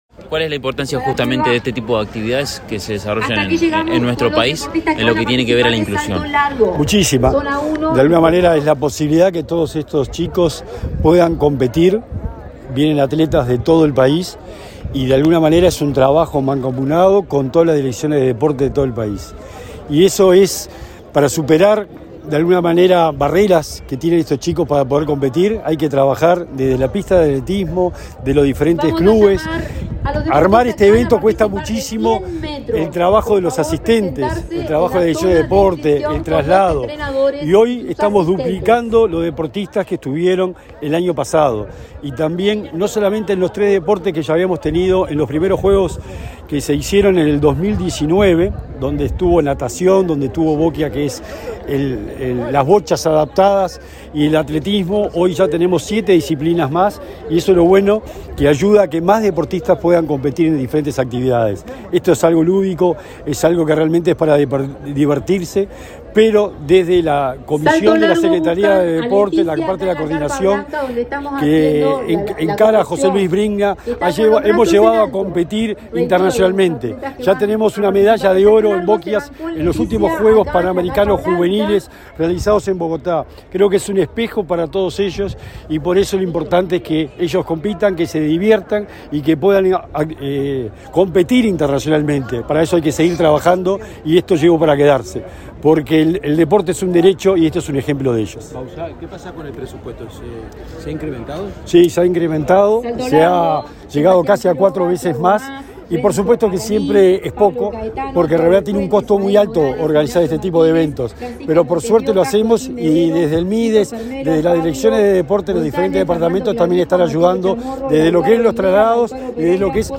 Declaraciones del secretario nacional del Deporte, Sebastián Bauzá
Declaraciones del secretario nacional del Deporte, Sebastián Bauzá 06/10/2023 Compartir Facebook X Copiar enlace WhatsApp LinkedIn El secretario nacional del Deporte, Sebastián Bauzá, y el secretario de la Presidencia, Álvaro Delgado, participaron en la ceremonia inaugural de los Juegos Paradeportivos Nacionales 2023, que se realizan en la pista de atletismo del Prado, en Montevideo. Luego Bauzá dialogó con la prensa.